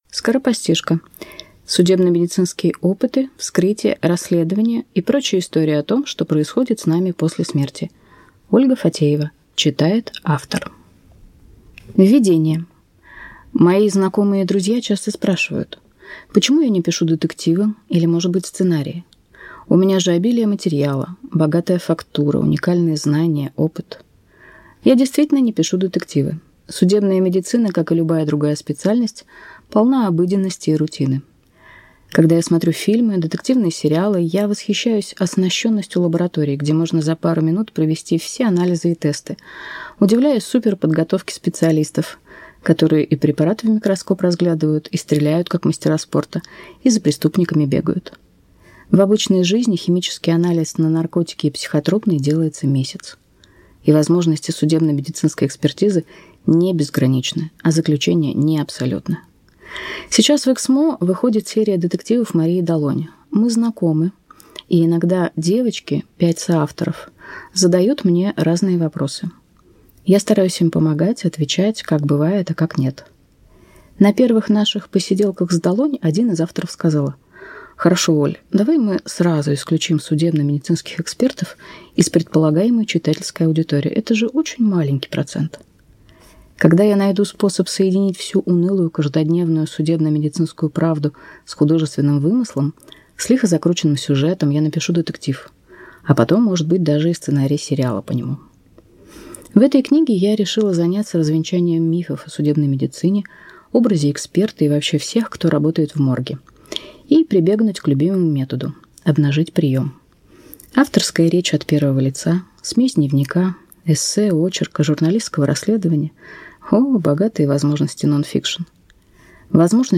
Аудиокнига Скоропостижка. Судебно-медицинские опыты, вскрытия, расследования и прочие истории о том, что происходит с нами после смерти | Библиотека аудиокниг